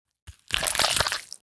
guts_and_gore_59.wav